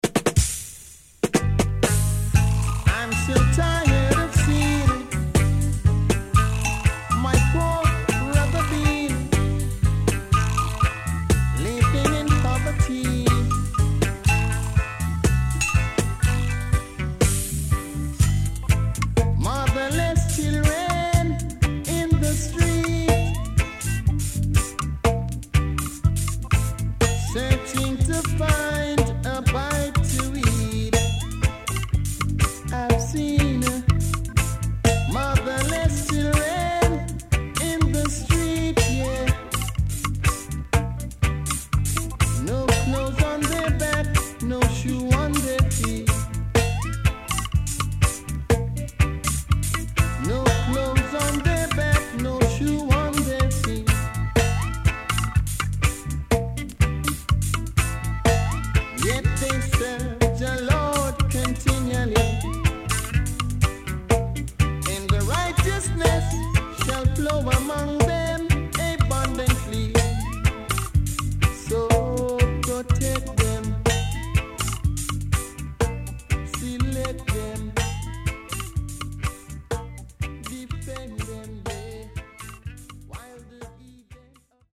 CONDITION SIDE A:EX
SIDE A:盤質は良好です。盤面はきれいです。